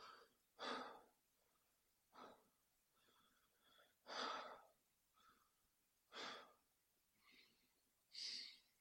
沉重的呼吸04
Tag: 呼气 吸气 呼气 呼吸 呼吸 弗利